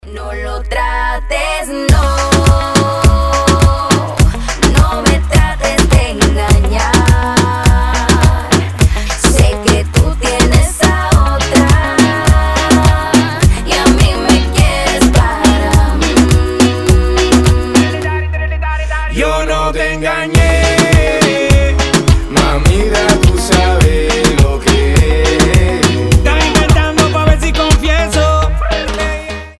• Качество: 320, Stereo
ритмичные
мужской вокал
женский вокал
заводные
дуэт
Reggaeton
Latin Pop